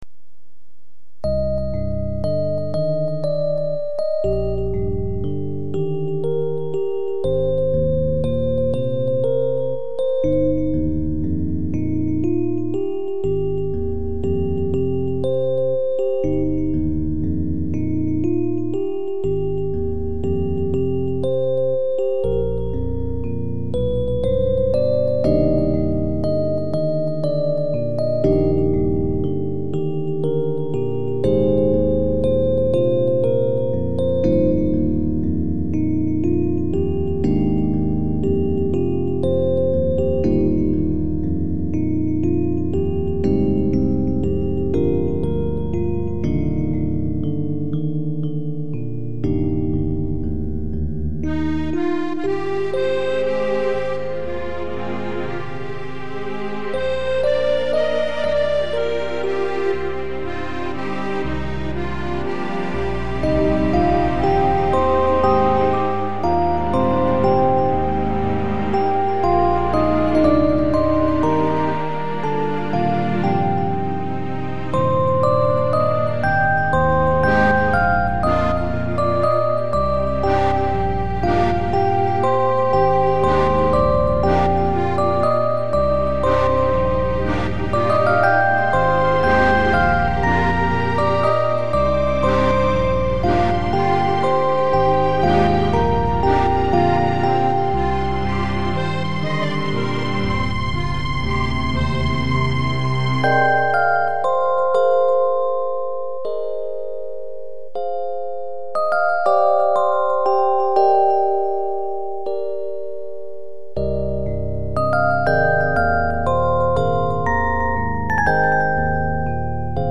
インストゥルメンタル